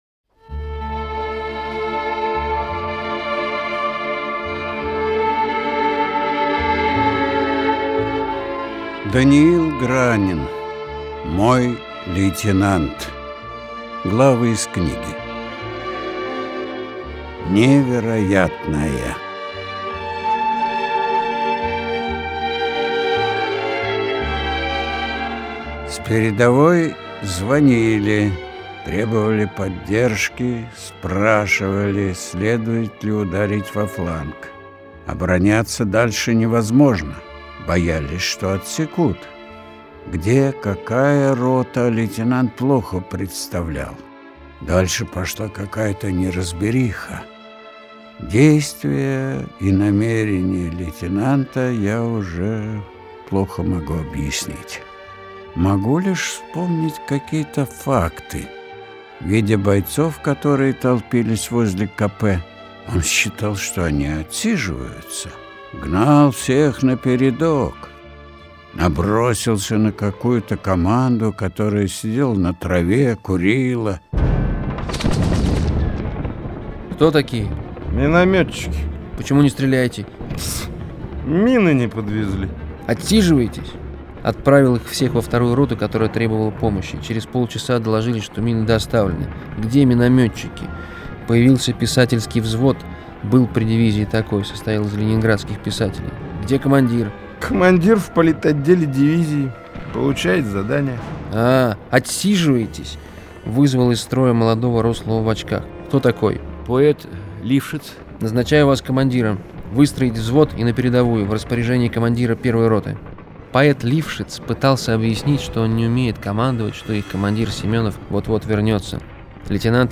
Литературные чтения (20:45)